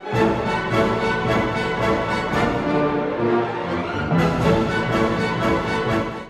これまでのイタリアの旋律が次々に現れます。
打楽器群が鮮やかな色彩を出し、最後はプレスティッシモの熱狂的な高まりで曲を終えます。
開放的なイタリアの気分が終始満ち溢れています！